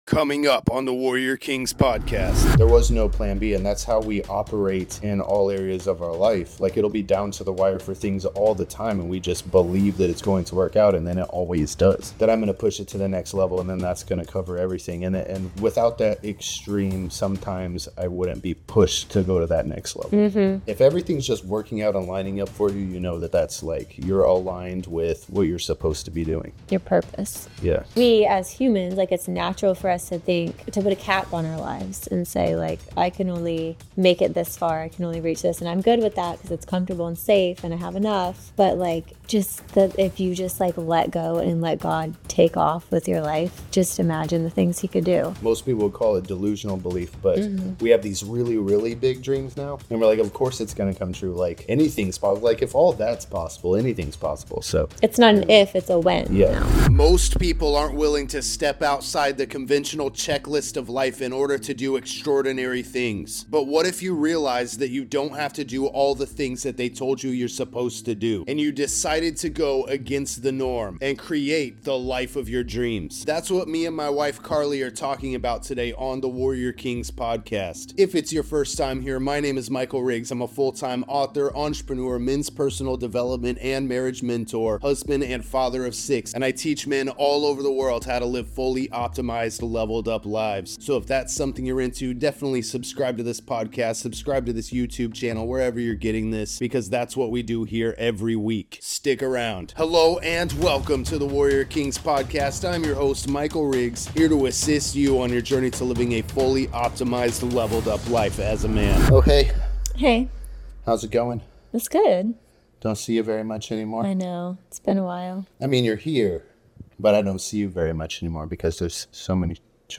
In this powerful conversation, we talk about marriage and success, faith and purpose, unconv